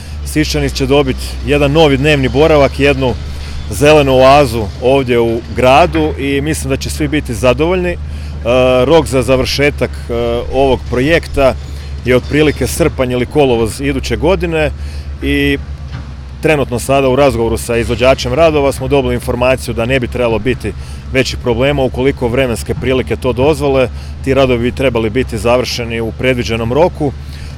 Nije riječ samo o kozmetučkom uređenju prostora, nego o projektu koji će donijeti novu vrijednost, ističe Orlić